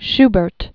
(shbərt, -bĕrt), Franz Peter 1797-1828.